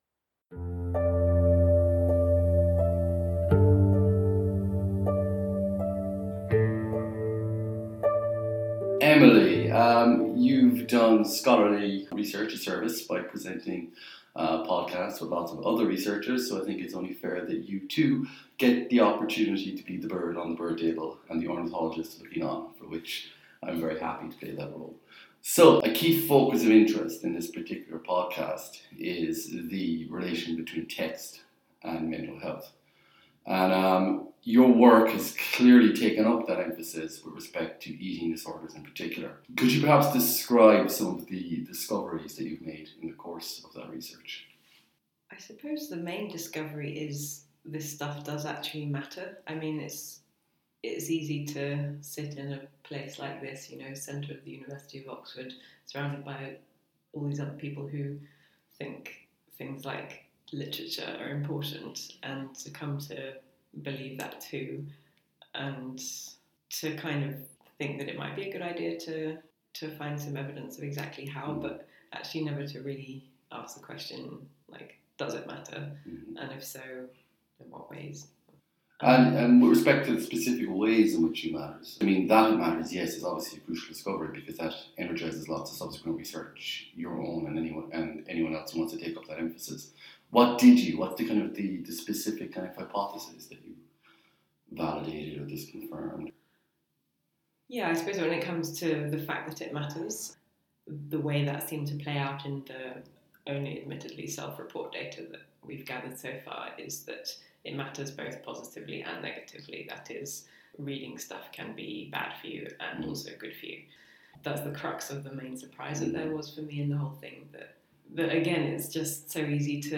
In this episode the tables are turned and I get interviewed